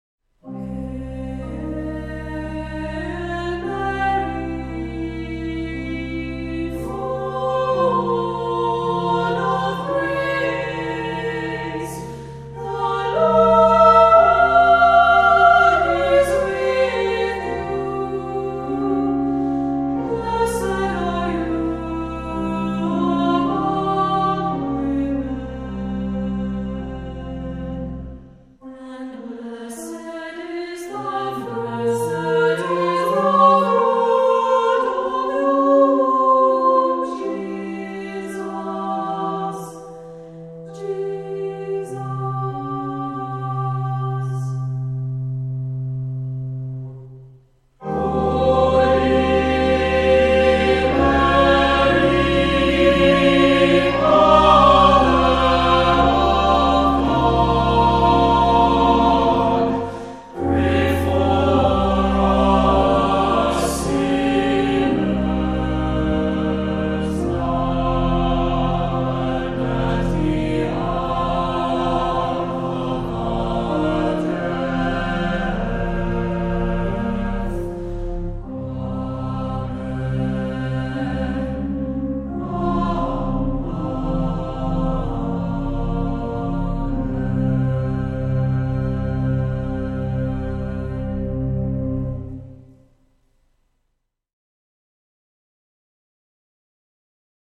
Voicing: 2-part Choir